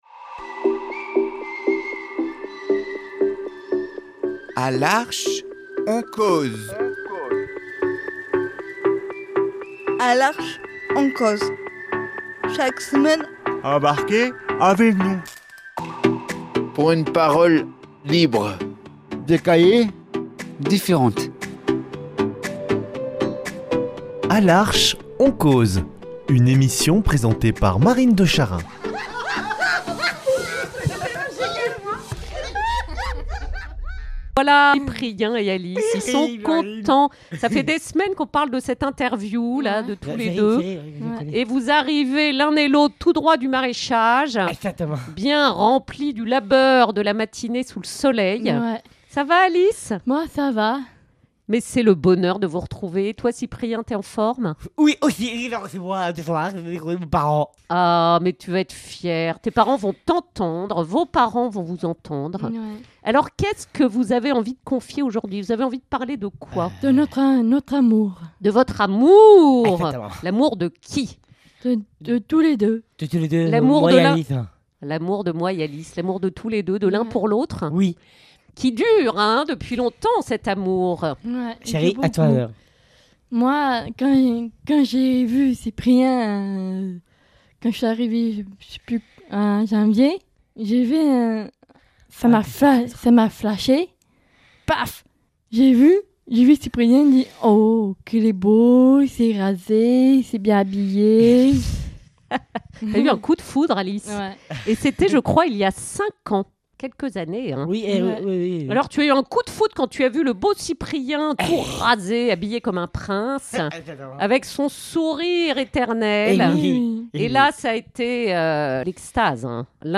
Par leurs confidences au micro, ils nous éclaboussent le cœur de leur lumière, merci tous les deux !